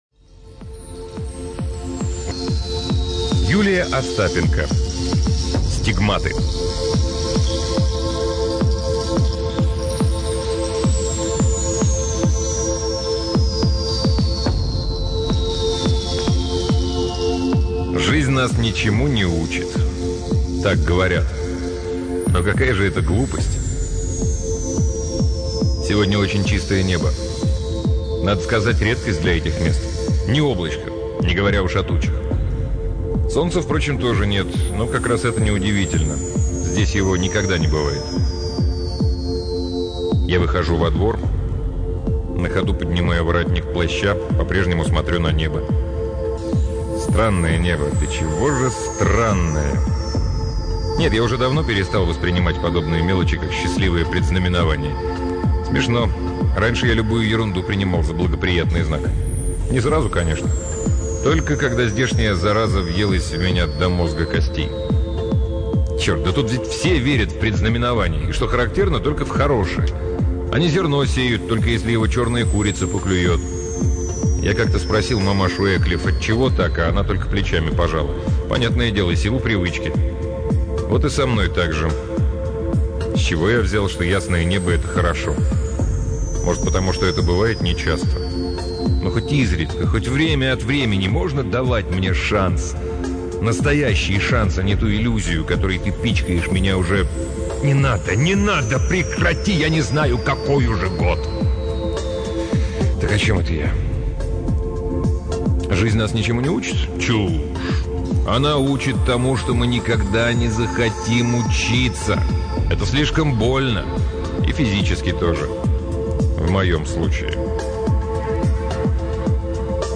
Аудиокниги передачи «Модель для сборки» онлайн